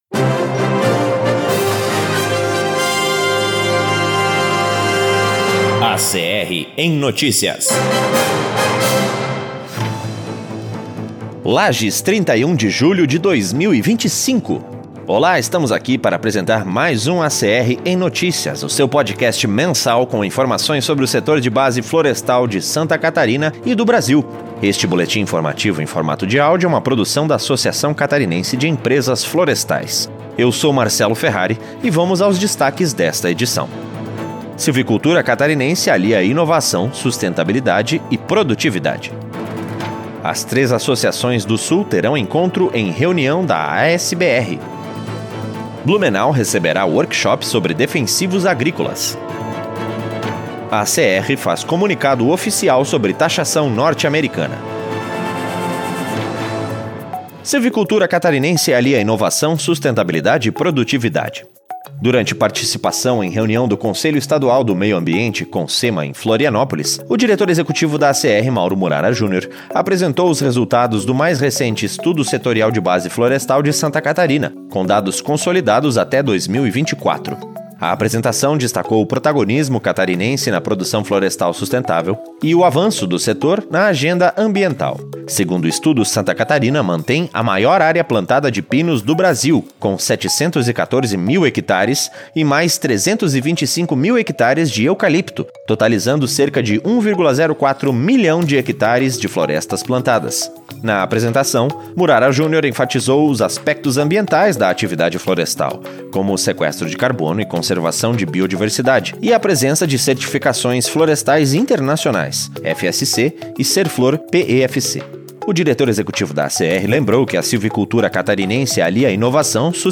Este boletim informativo em formato de áudio é uma produção da Associação Catarinense de Empresas Florestais.